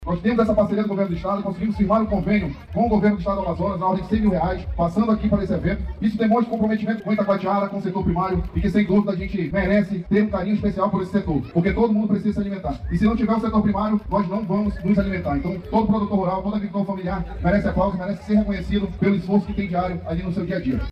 O Secretário de Estado de Produção Rural, Daniel Borges, participou da abertura do festival, na sexta-feira 20/10, e destaca a importância do incentivar o setor primário da economia.
SONORA-1-EXPOAGRO-ITACOATIARA-.mp3